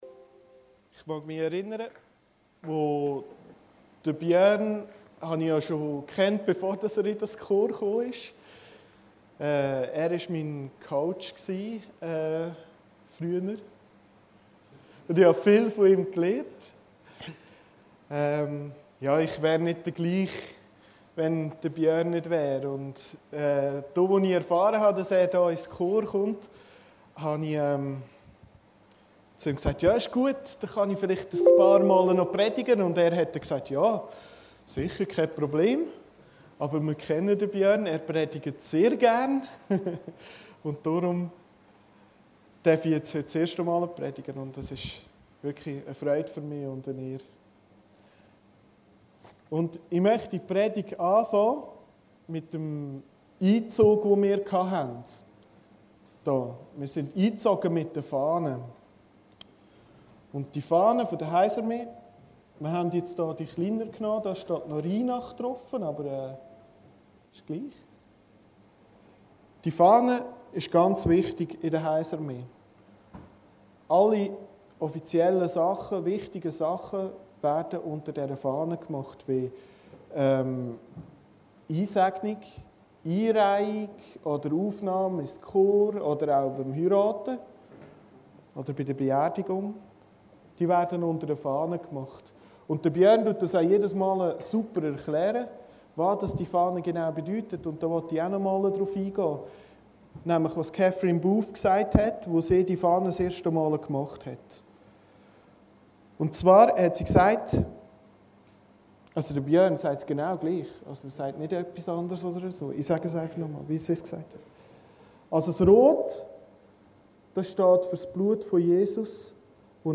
Predigten Heilsarmee Aargau Süd – Sieg mit Gott Predigten der Heilsarmee Aargau Süd Home Predigten Aktuelle Seite: Startseite Predigten Sieg mit Gott ↑↑↑ Montag, 13.